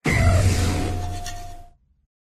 Cri_1021_EV.ogg